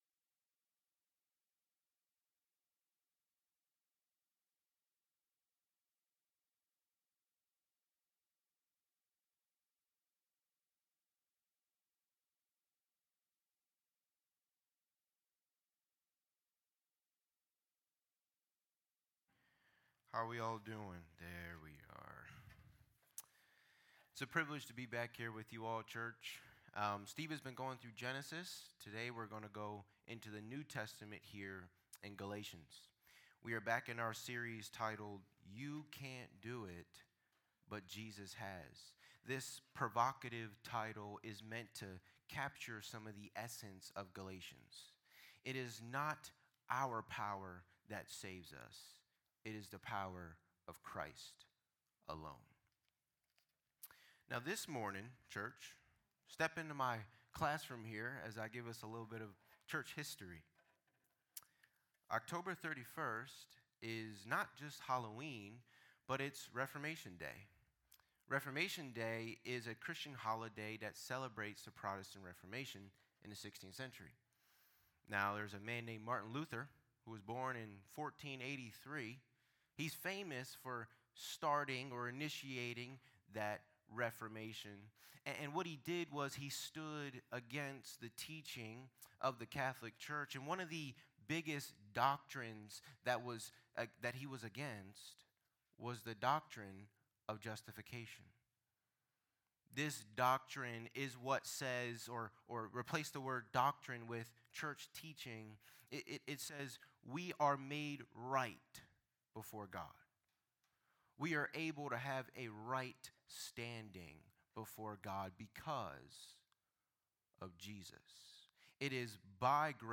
In this sermon, we take a look at how faith in Jesus' work on the cross makes us people of faith who live by faith.